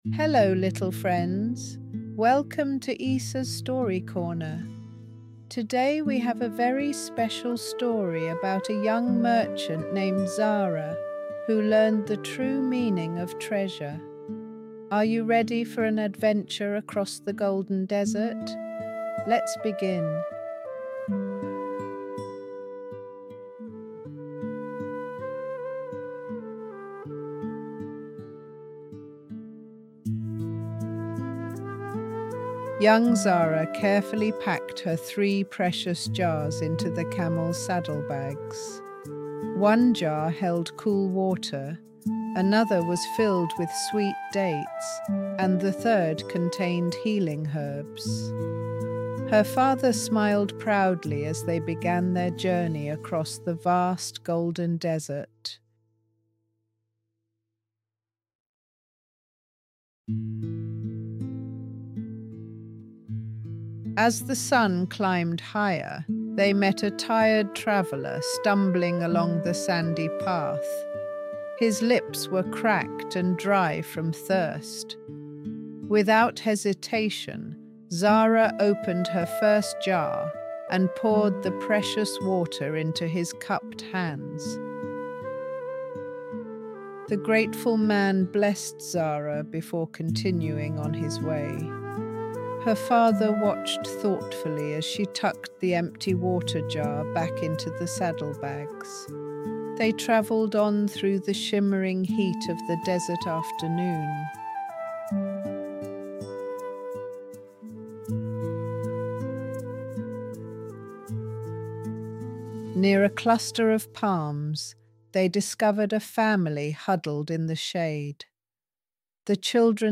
Audio Story